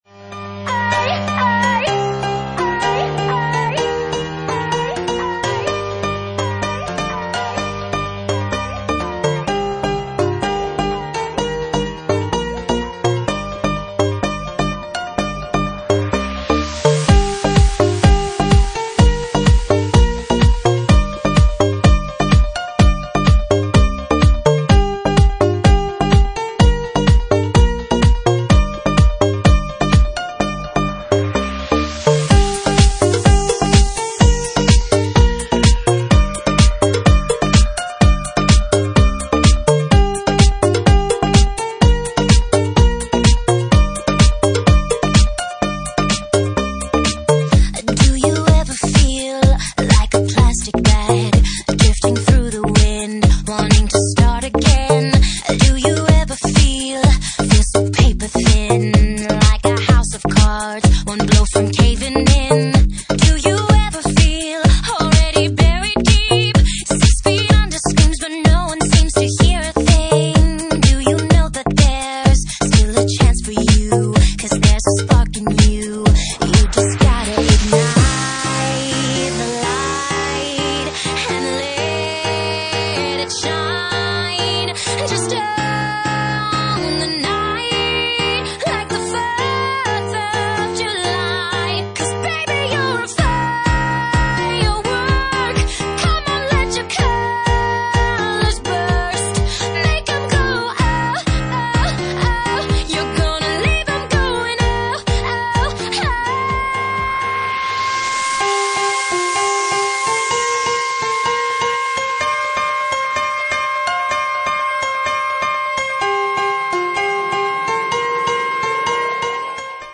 Genre:Jacking House
Jacking House at 127 bpm